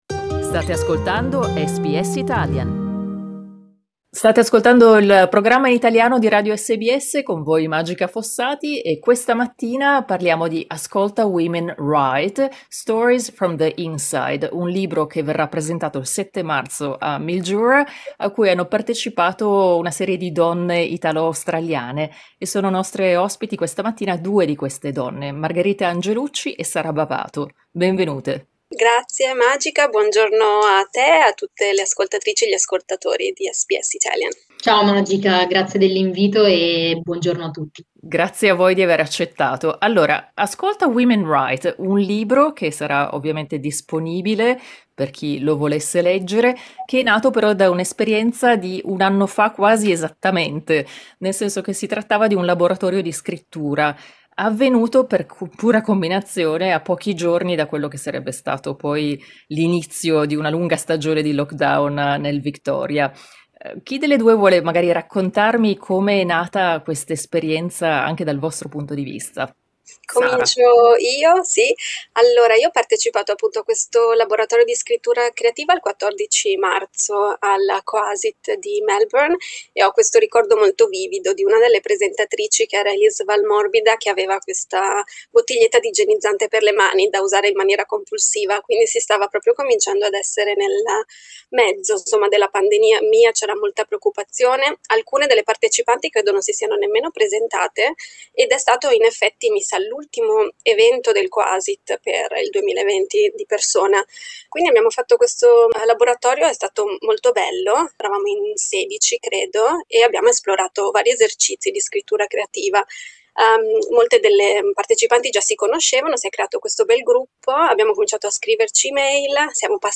Ascolta l'intervista a due delle partecipanti di questo gruppo di scrittura al femminile